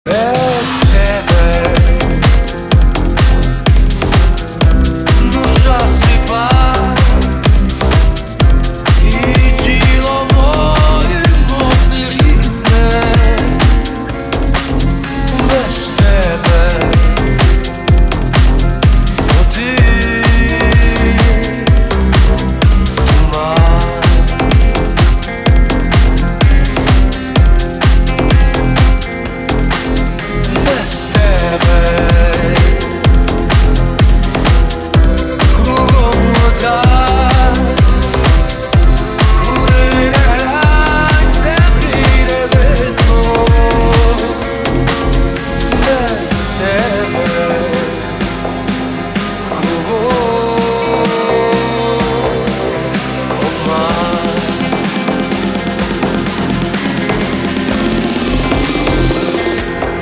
remix
deep house